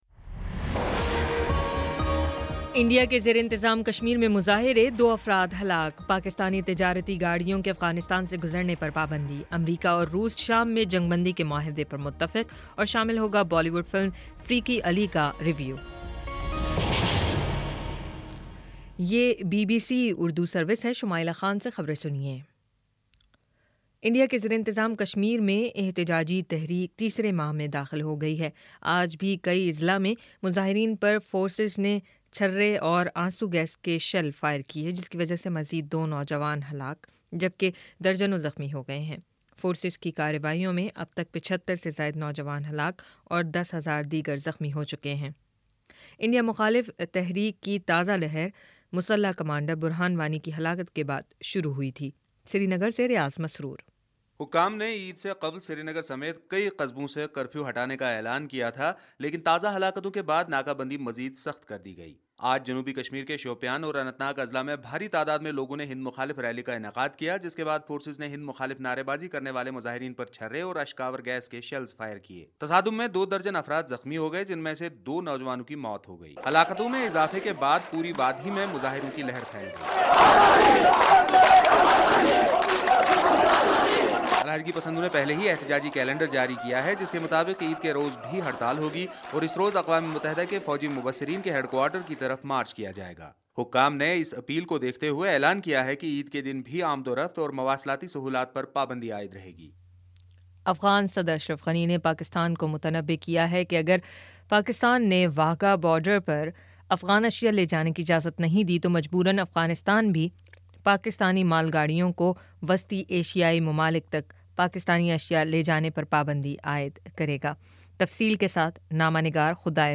ستمبر10 : شام چھ بجے کا نیوز بُلیٹن